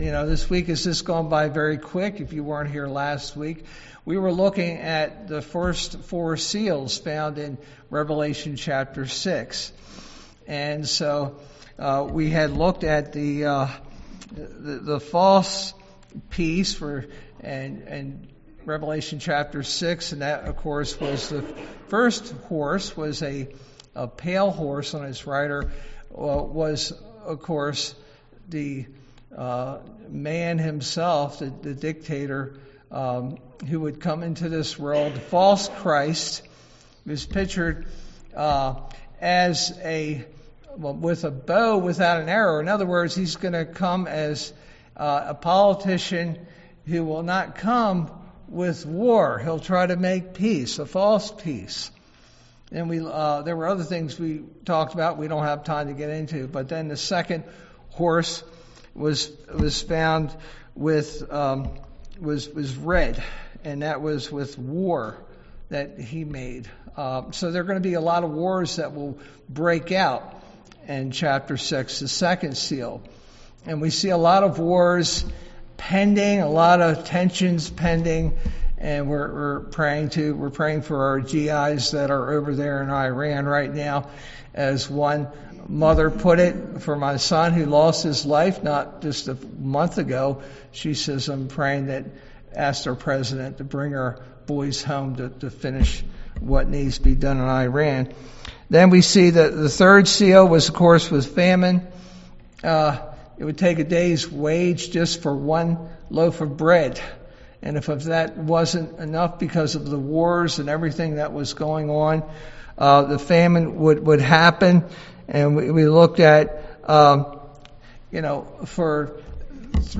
All Sermons Vengeance and Wrath 22 March 2026 Series